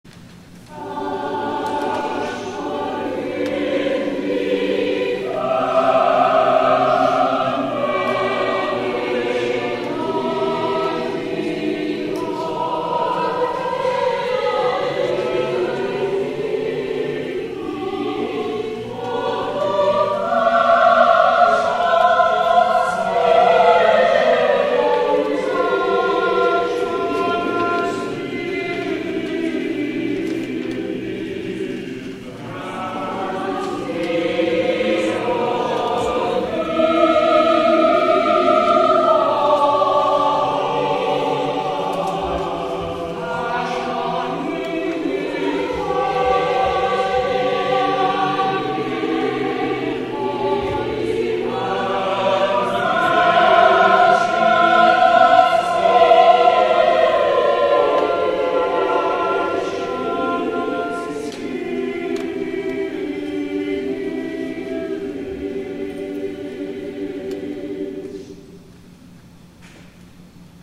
FOURTH SUNDAY OF LENT
*THE CHORAL RESPONSE